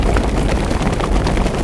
RUNINFEET1-R.wav